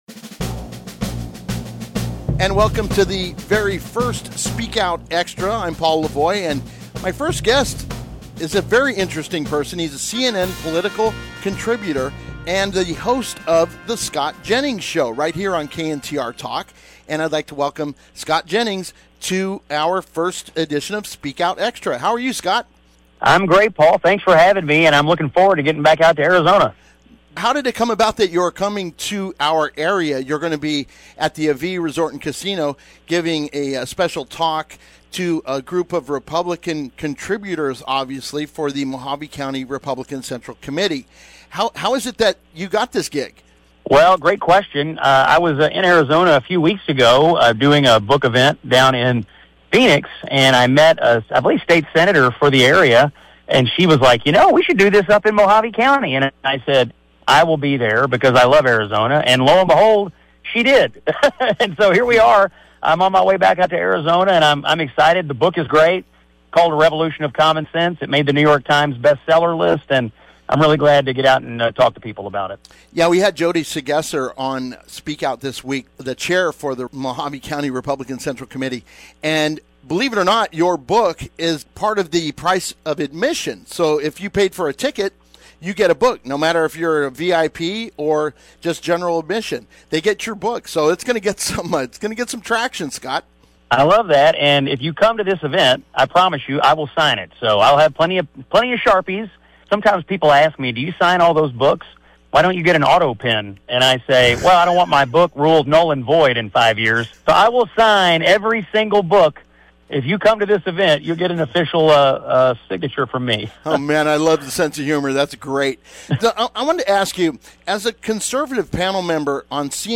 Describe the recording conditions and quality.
The “Extra – Uncut” segments found on this page are the complete interviews before they were cut down for time and content (does not include setup and/or outtakes).